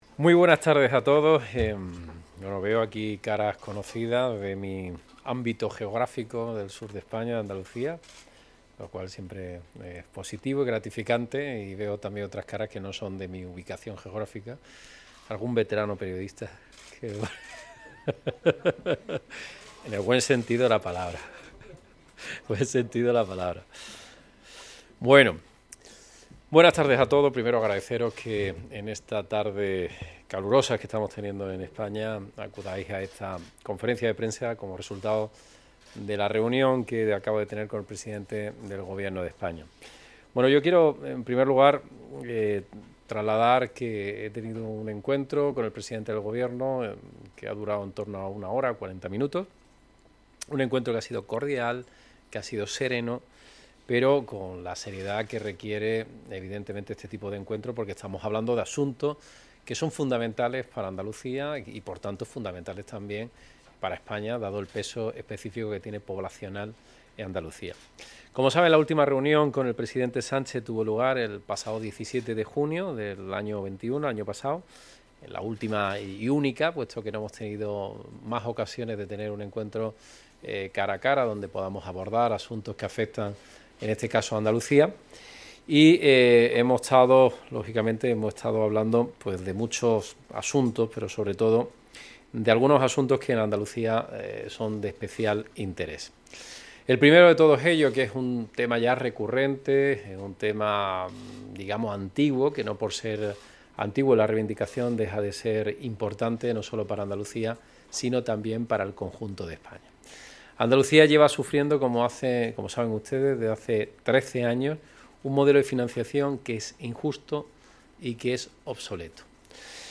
Así lo ha expuesto el presidente andaluz en la rueda de prensa en la que ha comparecido tras reunirse durante aproximadamente una hora con Pedro Sánchez en el Palacio de La Moncloa, en un encuentro que Moreno ha definido como «cordial».